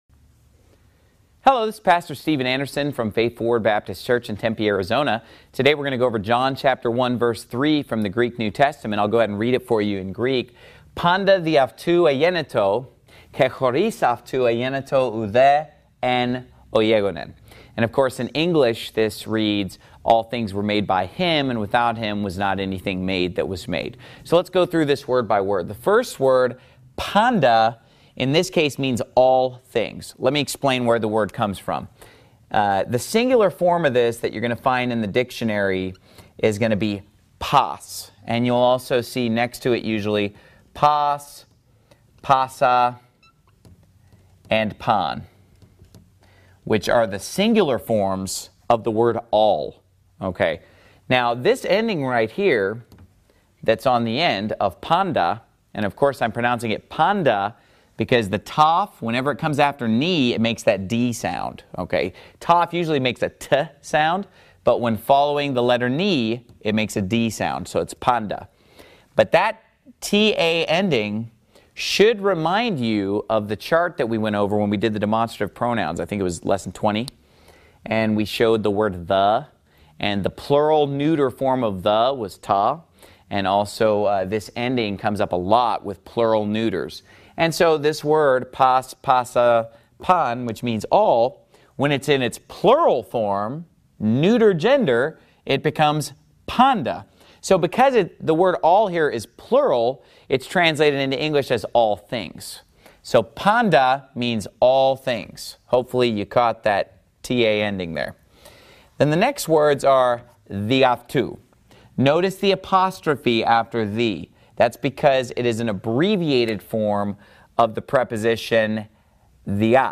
Greek 21 Greek Language Lesson With New Testament John 1 3
Greek_21_Greek_Language_Lesson_With_New_Testament_John_1_3.mp3